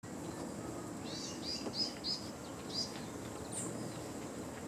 Ratona Común (Troglodytes musculus)
Nombre en inglés: Southern House Wren
Fase de la vida: Adulto
Localidad o área protegida: Reserva Ecológica Costanera Sur (RECS)
Condición: Silvestre
Certeza: Vocalización Grabada